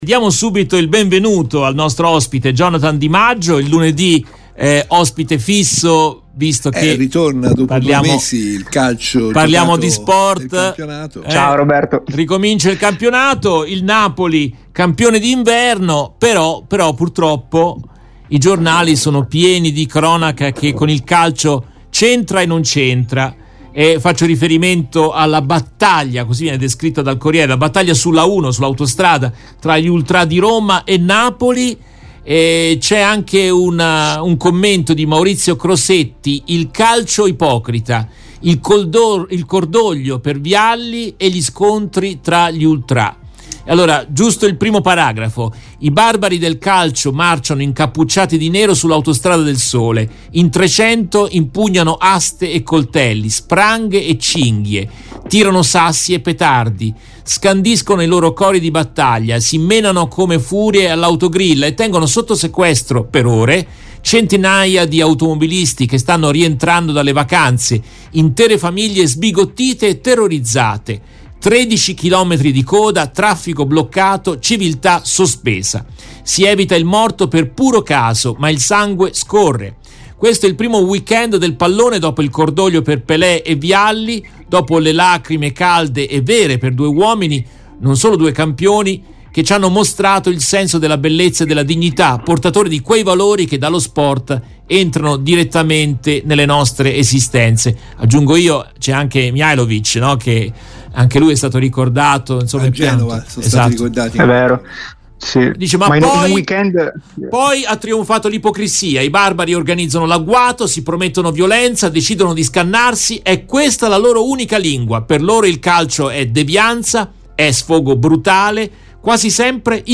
Nel corso della trasmissione in diretta del 09 genaio 2023